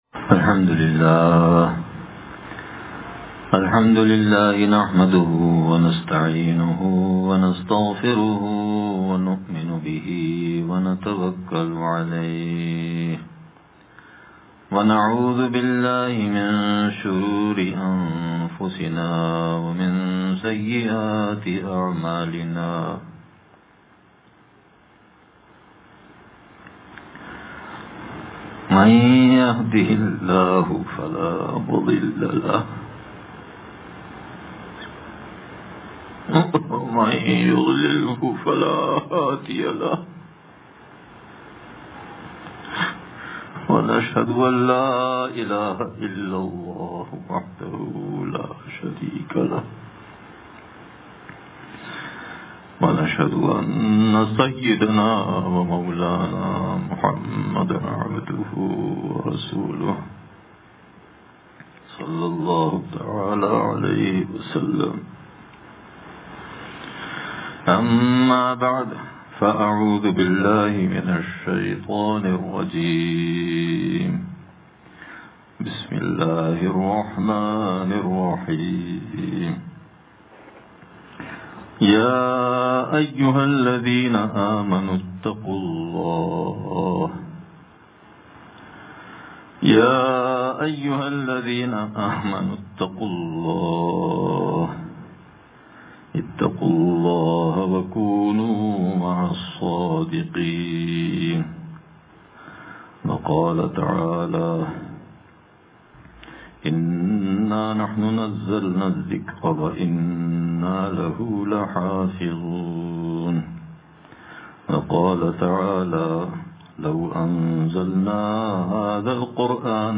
ختمِ قرآنِ کریم کے موقع پر اھم بیان